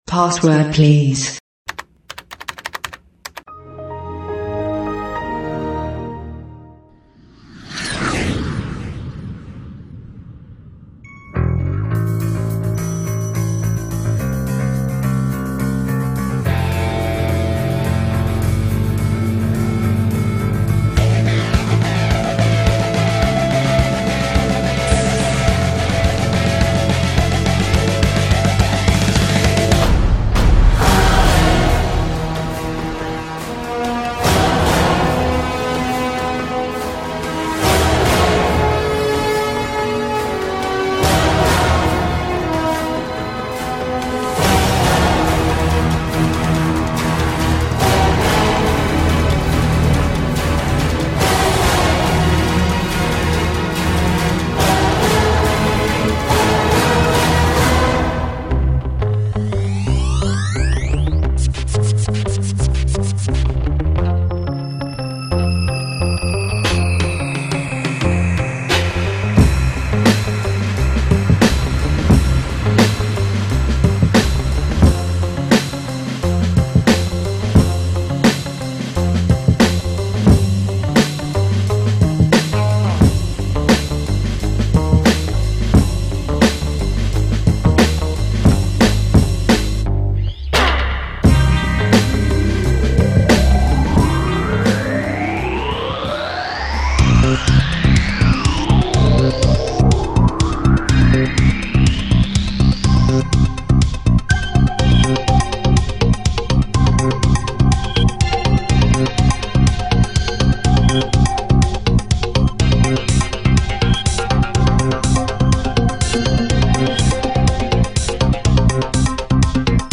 T�borov� zn�lky